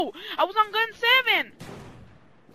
gun 7